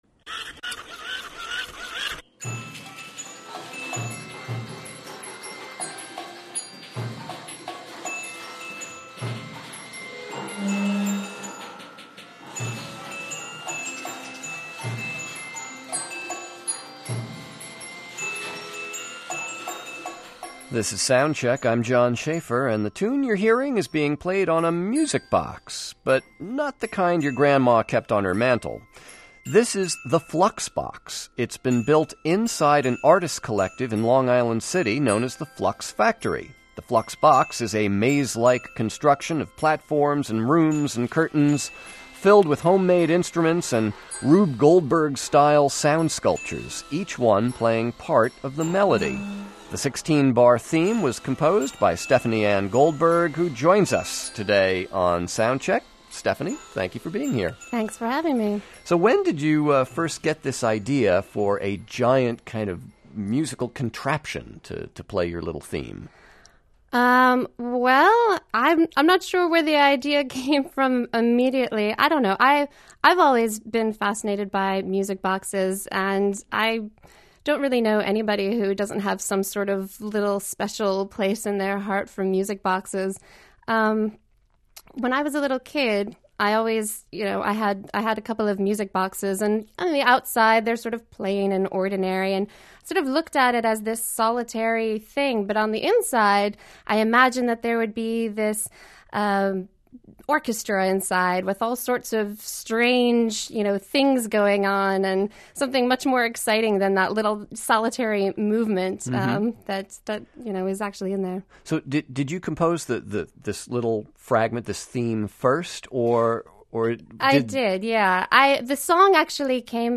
Interview on WNYC’s Soundcheck, April 2006